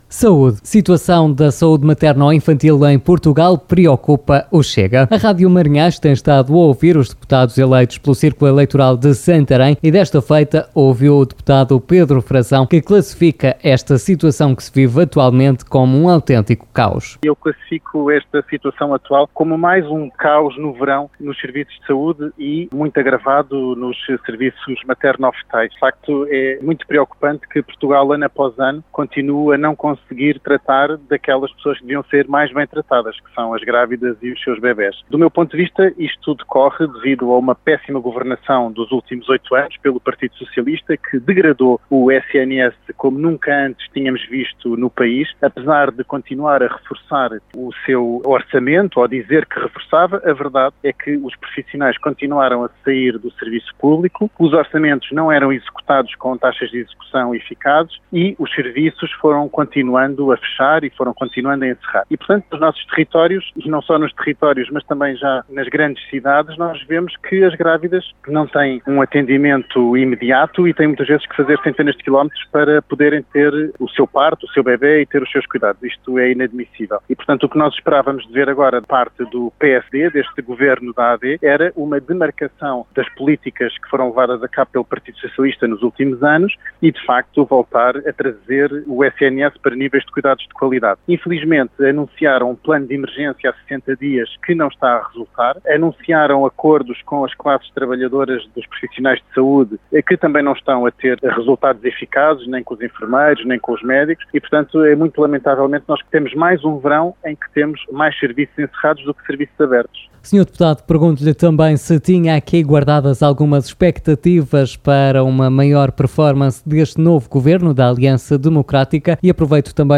Foram convidados deste Especial Informação o Deputado eleito pelo CHEGA, Pedro Frazão, Hugo Costa, do Partido Socialista e Inês Barroso, Deputada eleita pelo Partido Social Democrata.